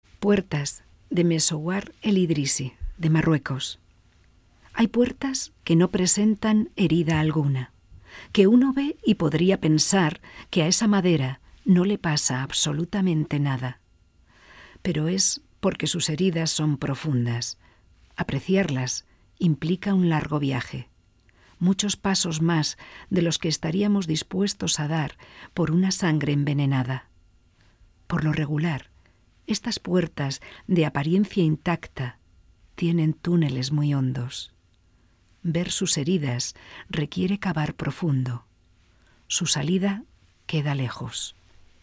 Poema del festival Poetas en Mayo, de cultura de Gasteiz, en Radio Vitoria.